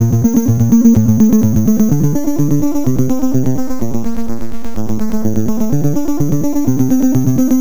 Clicky Sixteens Bb 126.wav